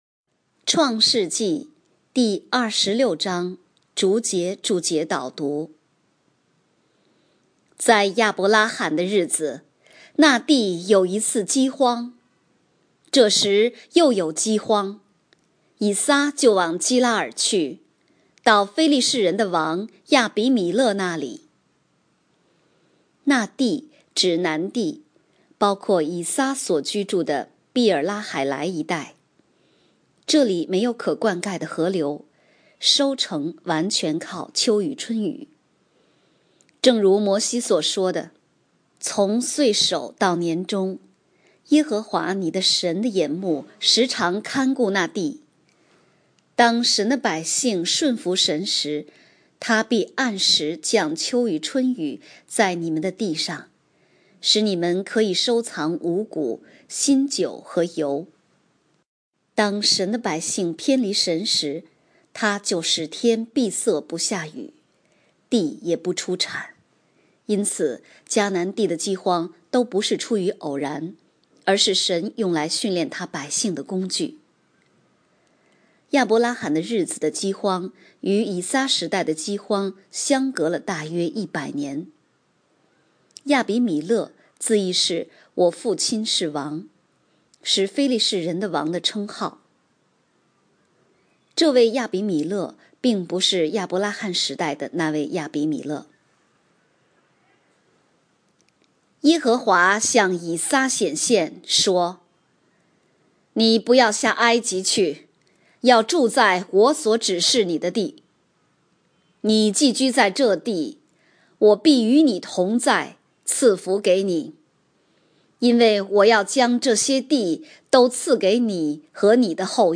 创世记第26章逐节注解、祷读